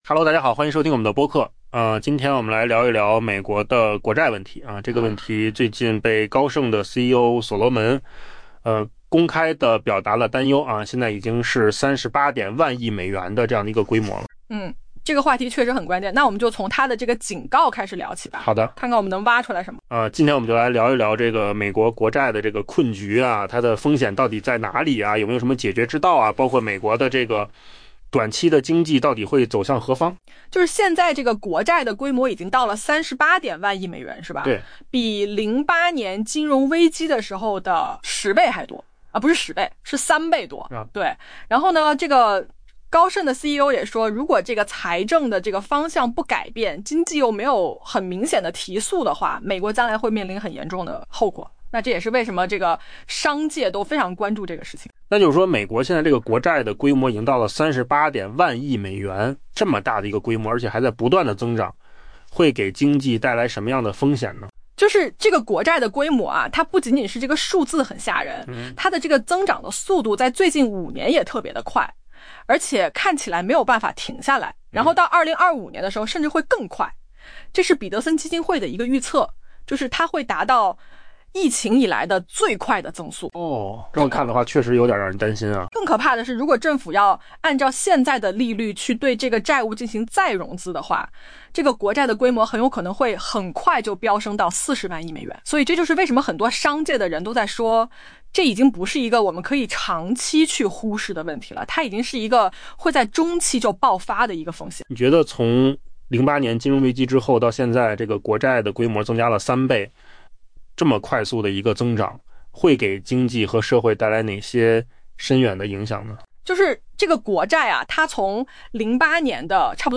【文章来源：金十数据】AI播客：换个方